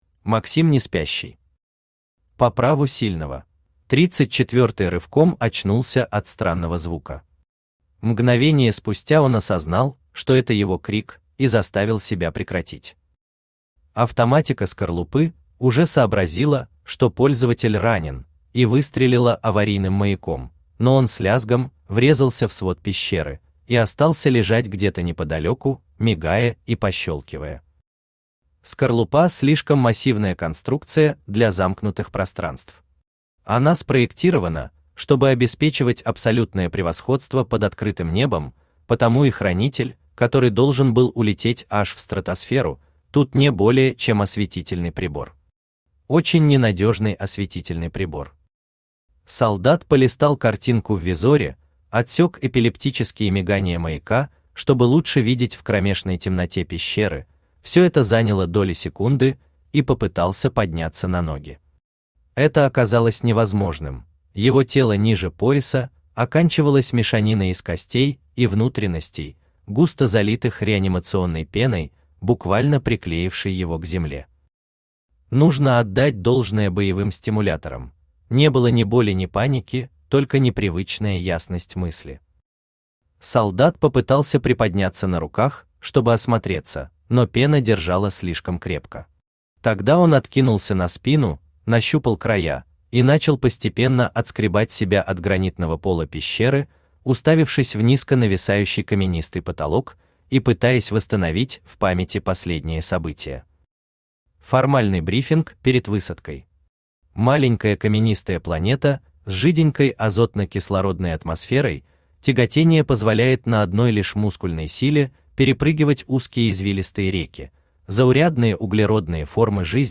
Аудиокнига По праву сильного | Библиотека аудиокниг
Aудиокнига По праву сильного Автор Максим Олегович Неспящий Читает аудиокнигу Авточтец ЛитРес.